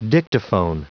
Prononciation du mot dictaphone en anglais (fichier audio)
Prononciation du mot : dictaphone